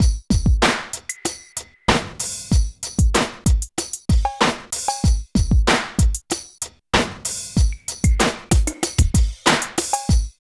35 LOOP   -L.wav